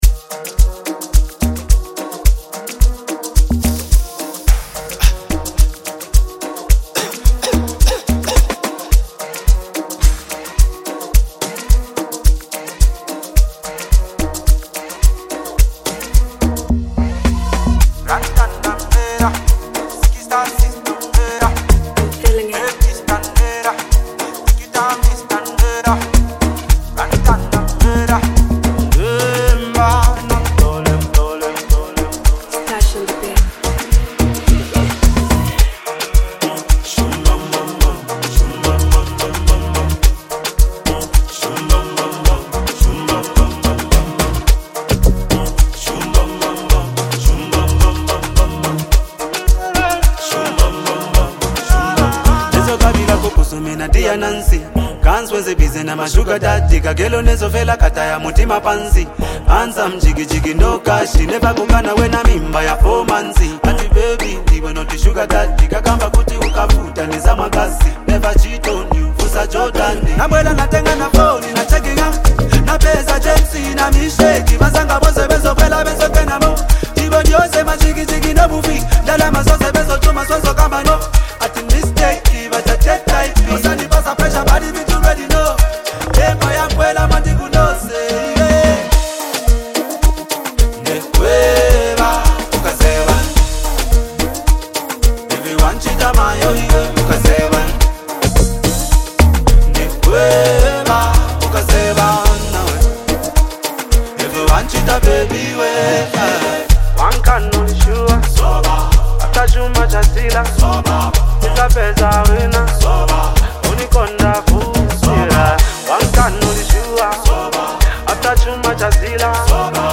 The emotions in his voice add warmth to every word.
This song is a perfect mix of emotion and groove.